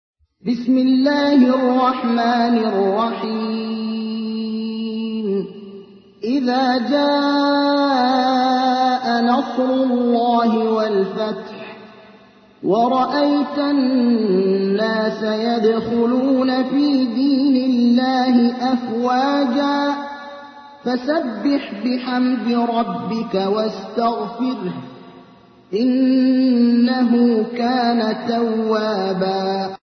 تحميل : 110. سورة النصر / القارئ ابراهيم الأخضر / القرآن الكريم / موقع يا حسين